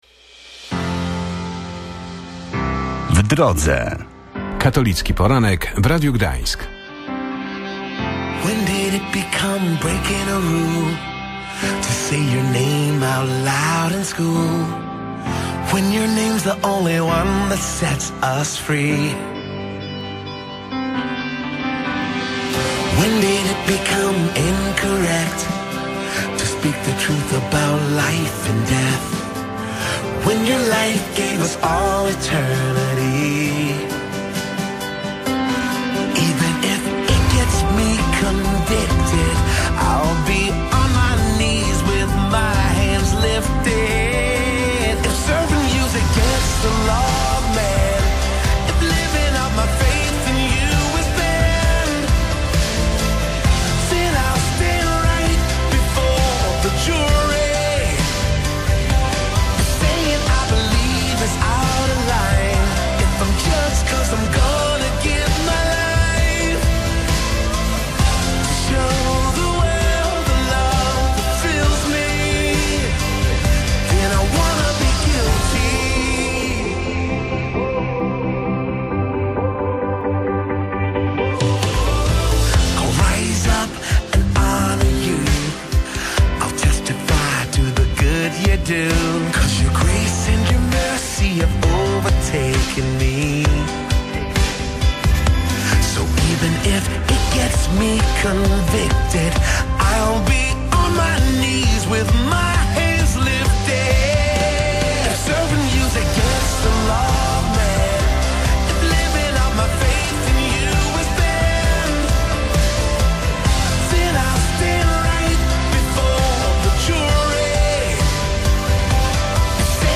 rozmawiała o tym wydarzeniu z mieszkańcami Gdańska